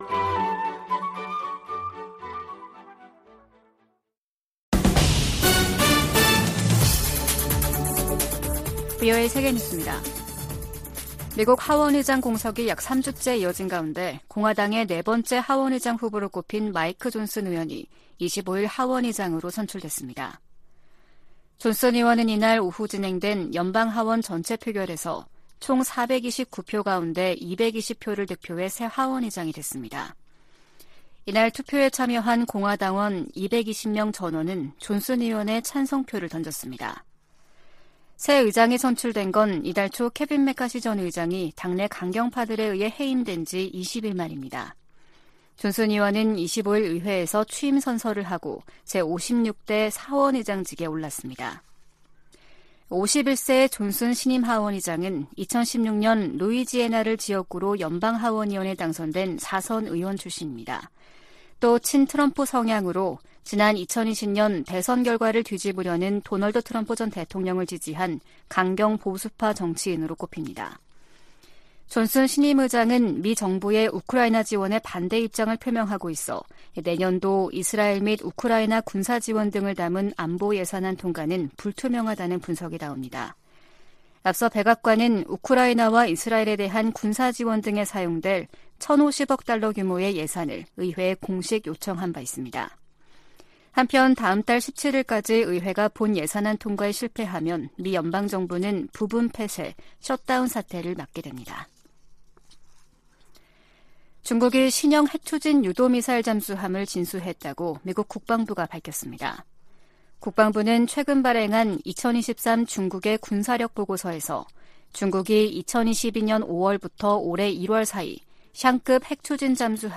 VOA 한국어 아침 뉴스 프로그램 '워싱턴 뉴스 광장' 2023년 10월 26일 방송입니다. 유럽연합(EU)이 탈북민 강제북송 문제가 포함된 북한인권 결의안을 유엔총회에 제출할 계획입니다. 한국은 유엔총회에서 북한과 러시아에 불법 무기거래를 즉각 중단라고 촉구했습니다.